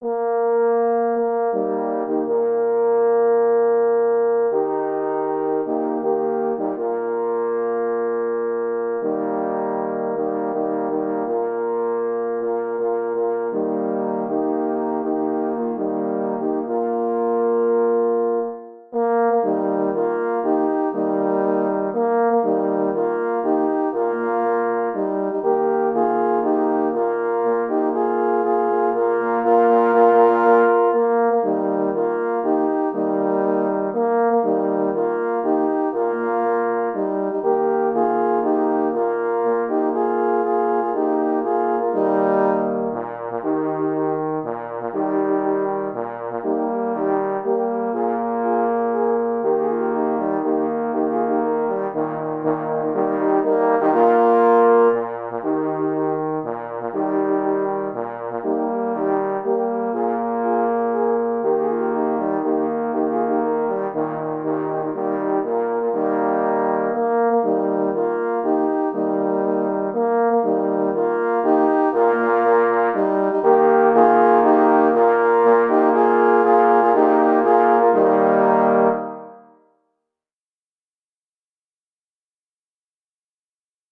Opis zasobu: utwór koncertowy na 4 […]
Niech darzy bór – na 4 parforsy w stroju Es